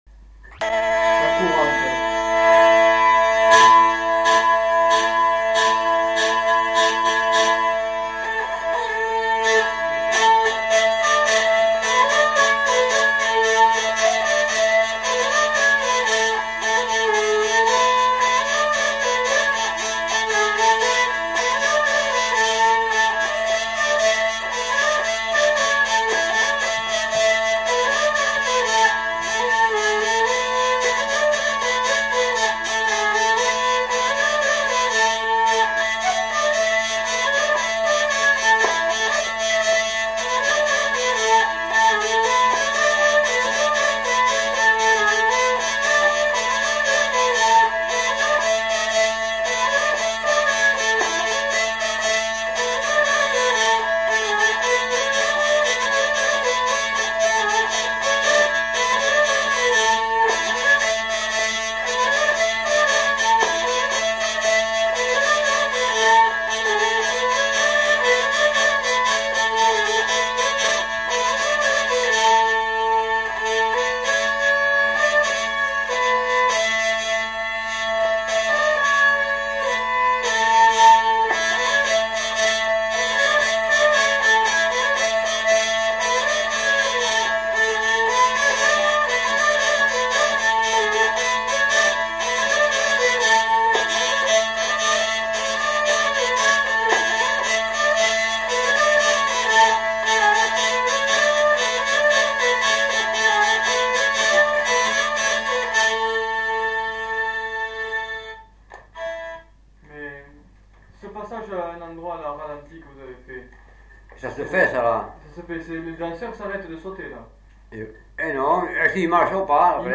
Courante
Aire culturelle : Gabardan
Lieu : Herré
Genre : morceau instrumental
Instrument de musique : vielle à roue
Danse : courante